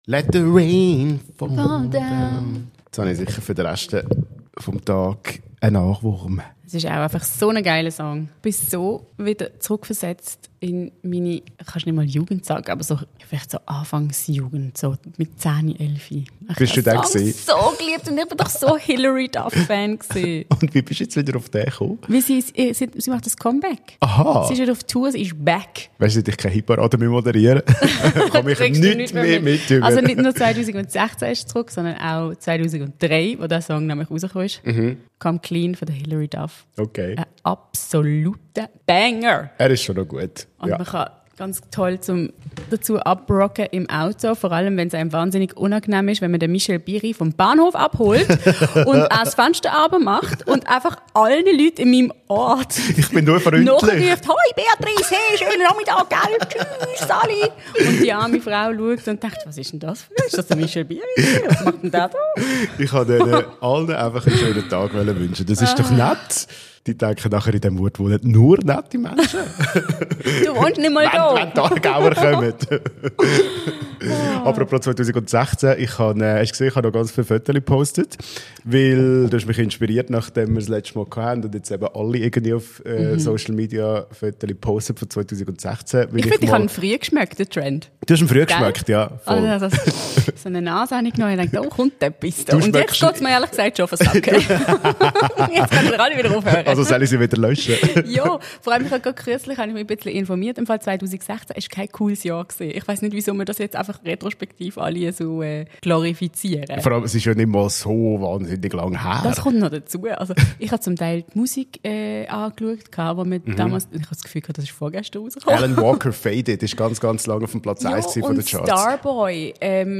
Eure zwei liebsten Moderationsdüsen sind zurück aus Paris und Wengen